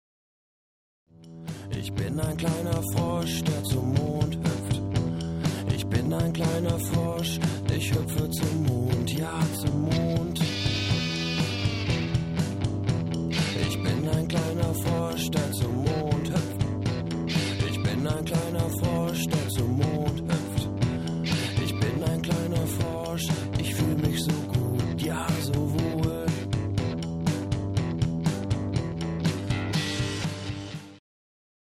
wavigen Klänge